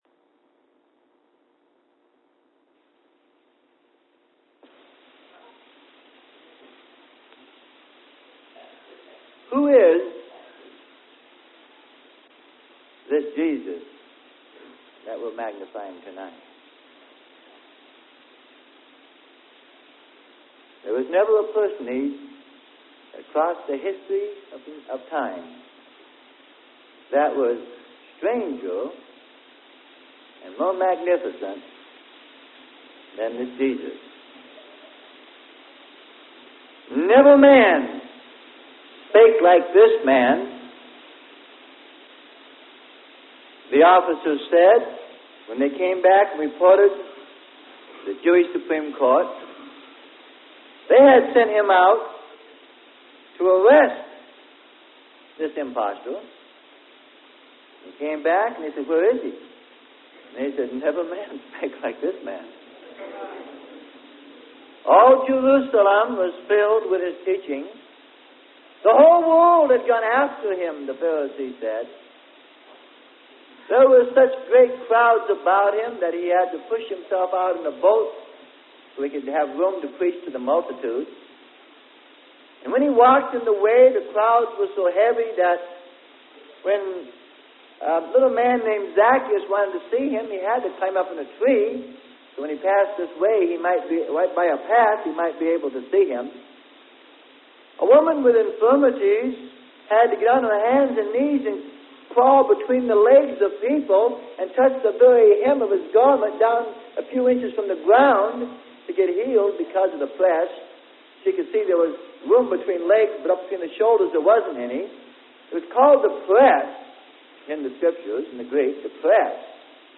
Sermon: Who Is This Jesus?